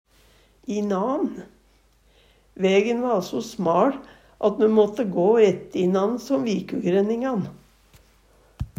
inan - Numedalsmål (en-US)
DIALEKTORD PÅ NORMERT NORSK inan kvarandre, hinanden, einannan Eksempel på bruk Vægen va so smaL at me måtte gå ette inan som vikugræningan.